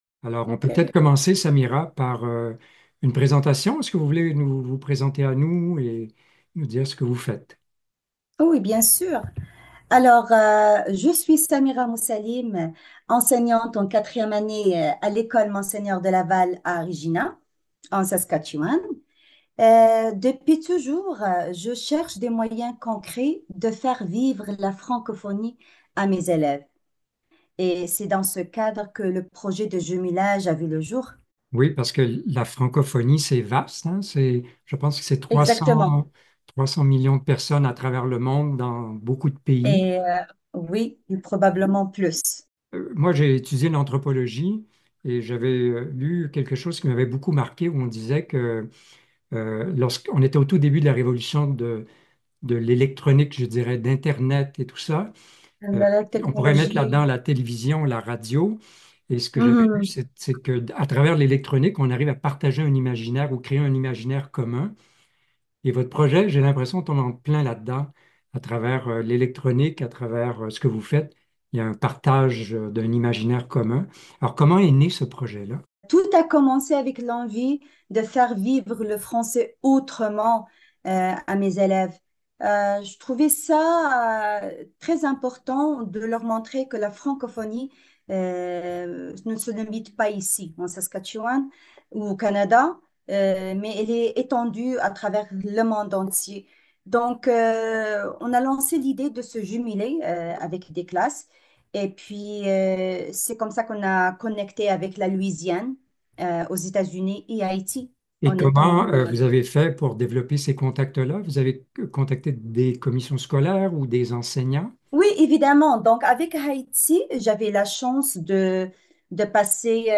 Dans cette entrevue